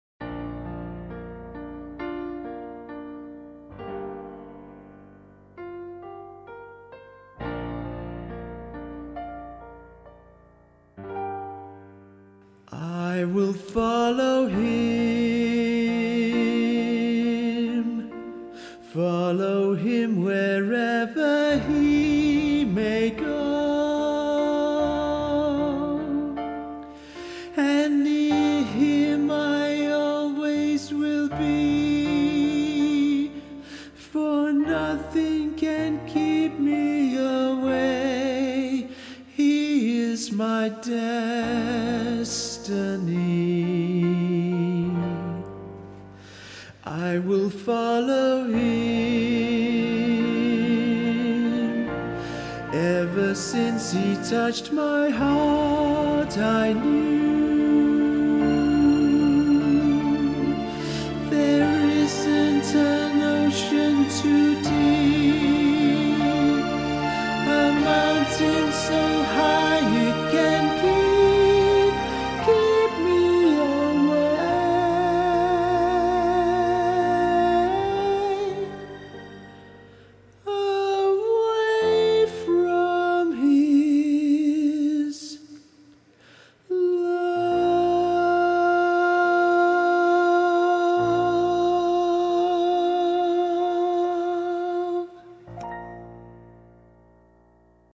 Choir
Sister Act - Low Part
I-Will-Follow-Him-intro-LOW-PART.mp3